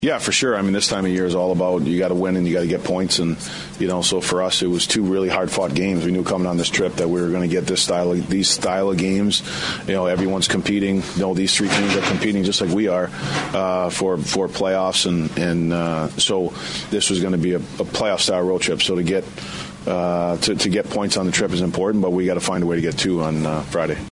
Head Coach John Hynes talks about the importance of getting points on this road trip.